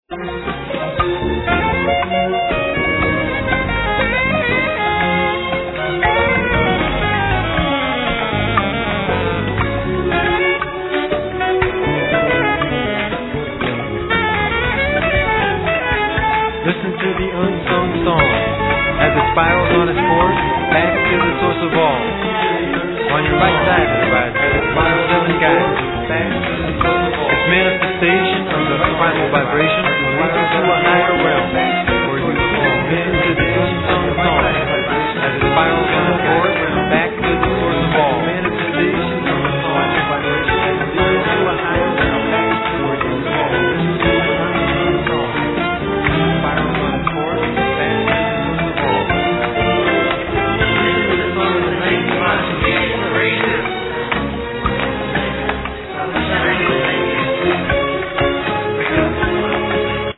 Soprano & Alto saxophone, Flute, Piano, Voice
Vibraphone, Voice, Glockenspiel
Drums, Percussions